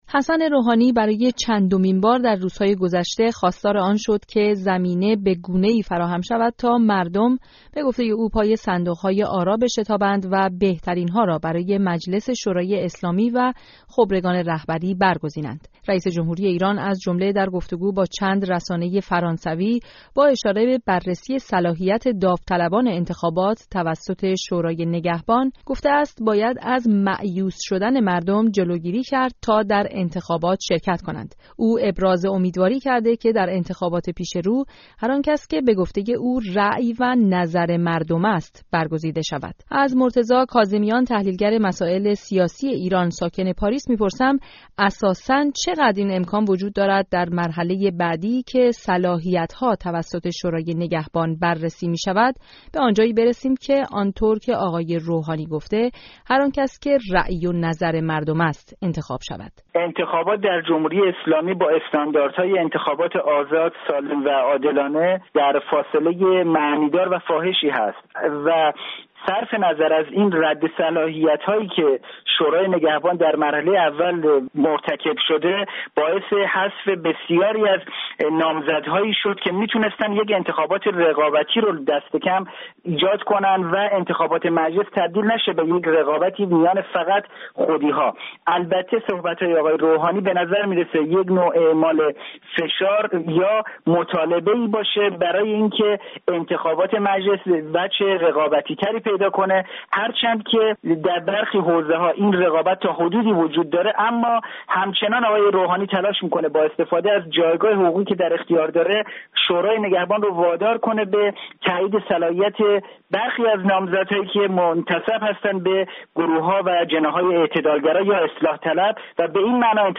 گزارش رادیویی درباره آخرین اظهار نظرها در مورد تعیین صلاحیت نامزدها